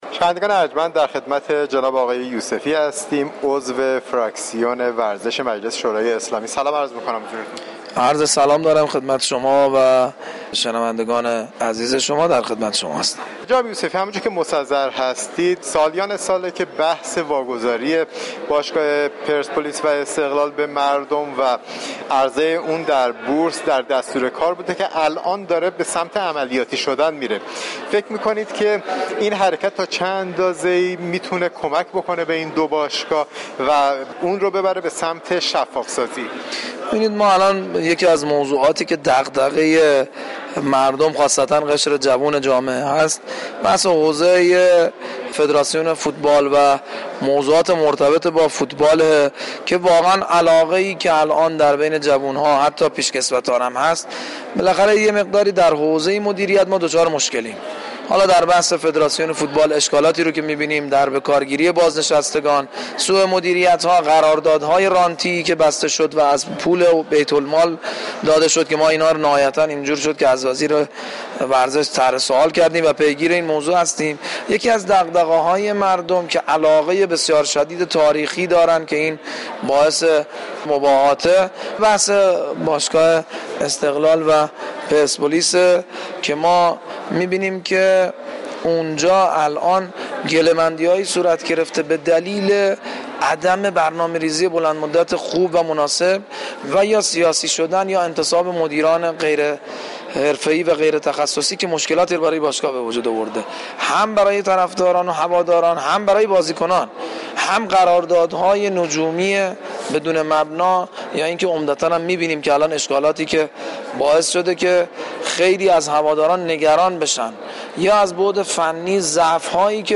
مجتبی یوسفی عضو فراكسیون ورزش مجلس در گفت و گوی اختصاصی